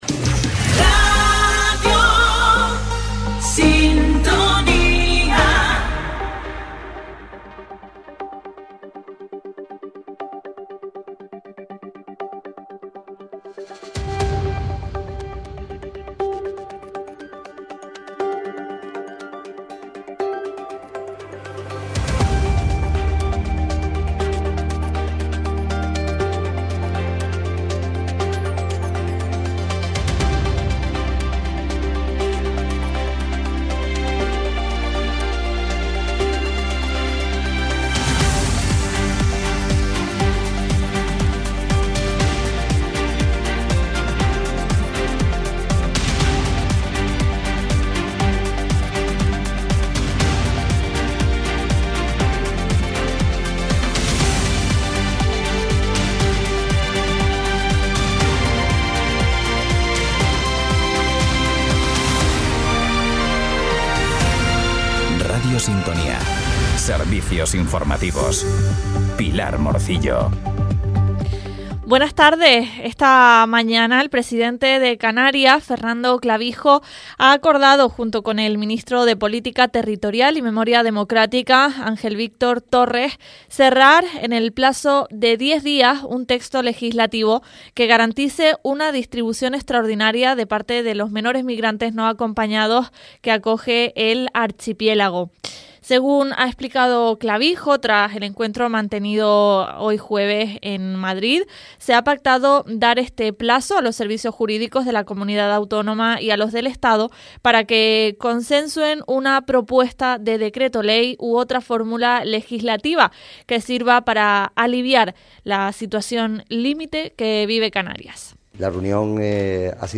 Informativos en Radio Sintonía - 09.01.25
En Radio Sintonía Fuerteventura les mantenemos al día de la actualidad local y regional en nuestros informativos diarios a las 9.30 y 13.15 horas. Por espacio de 15 minutos acercamos a la audiencia lo más destacado de los distintos municipios de la isla, sin perder la atención en las noticias regionales de interés general.